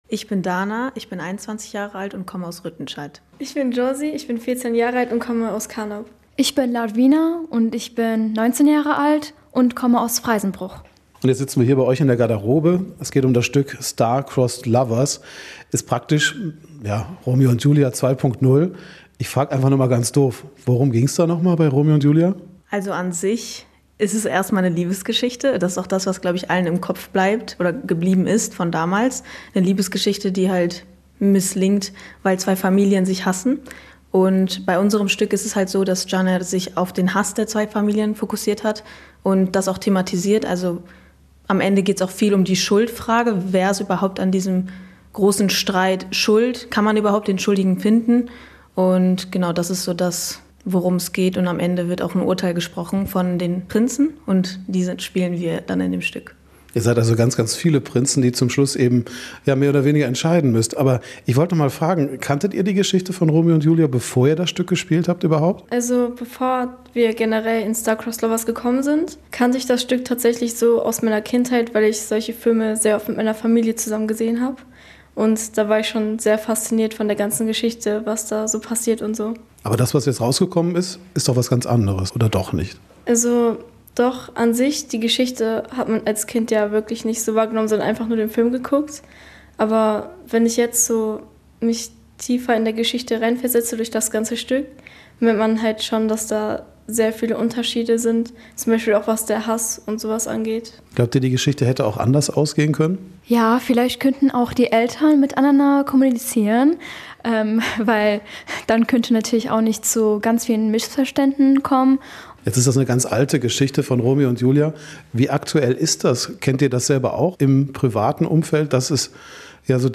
Wir waren Backstage bei den Proben mit dabei.